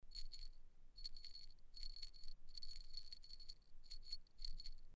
Sound recordings of original pellet bells from the cemeteries Radvaň nad Dunajom-Žitava I (Žitavská Tôň), Holiare and Skalika, SK.
Original sound of Avar pellet bells from the cemeteries
Sound recording of original_pellet bell_ 2139_Radvaň_nad_Dunajom_Žitava_I_grave_10 0.08 MB